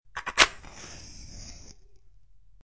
descargar sonido mp3 fosforos